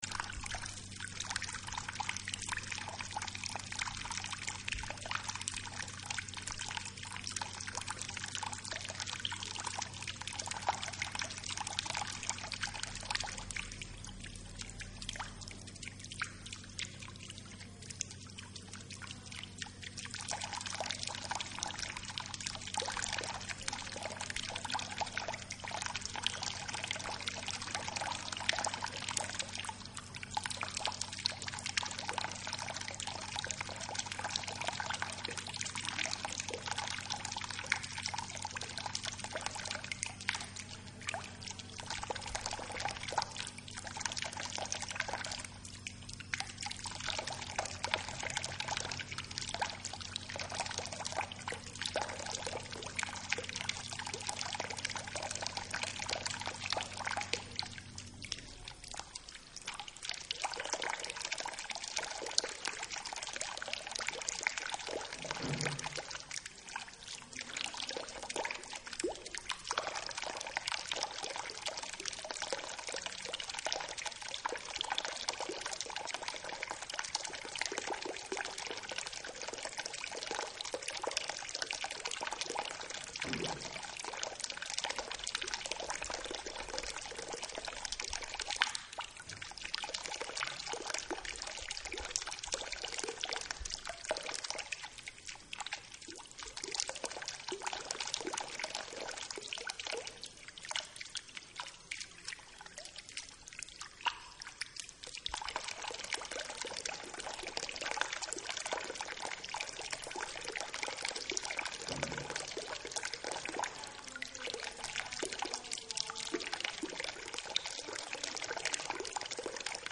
The sound of water in the Carvajales palace
En el centro encontramos una fuente con una base octogonal, con molduras redondeadas en los extremos, fuste abalaustrado, taza lisa (de media naranja) y cuatro caños de agua en el borde.
fuenteprincipalpatiocentrodocumentac-iesalbayzin-.mp3